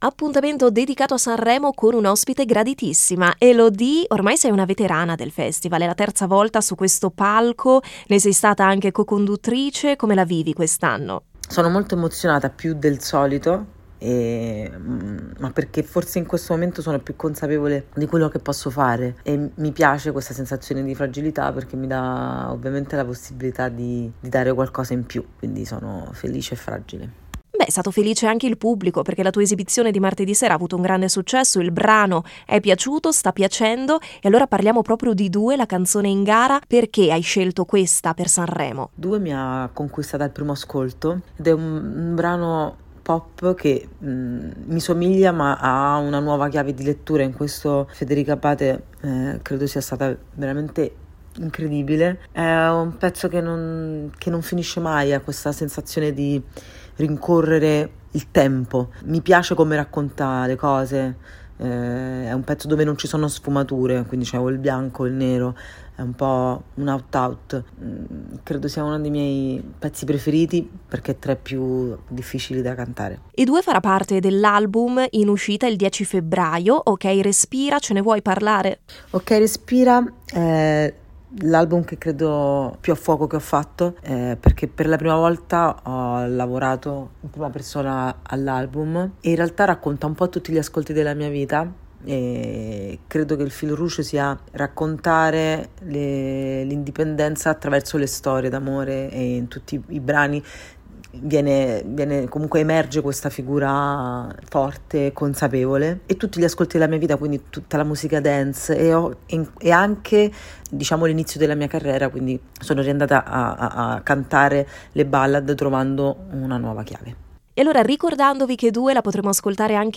Radio Pico intervista Elodie